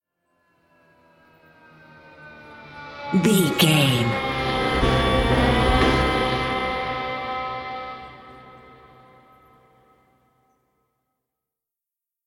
Aeolian/Minor
synthesiser
percussion
ominous
dark
suspense
haunting
creepy